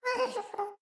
moan7.mp3